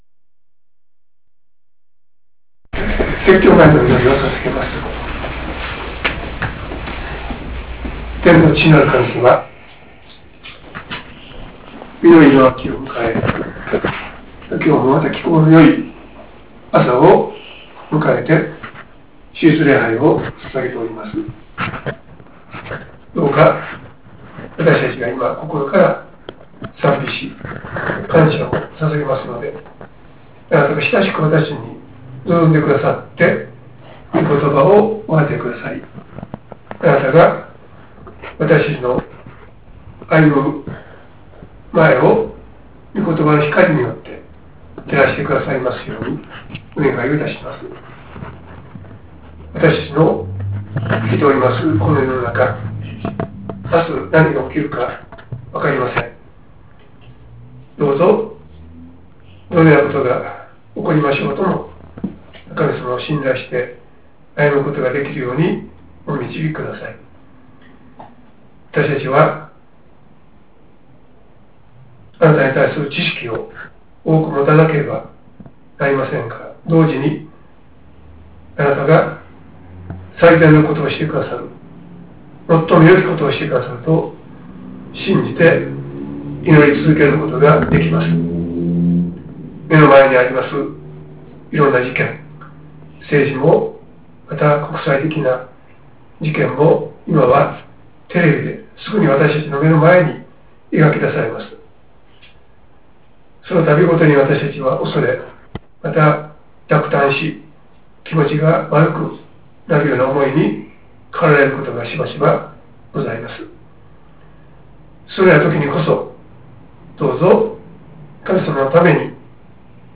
←クリックで説教が聴けます 説教「わたしに従いなさい」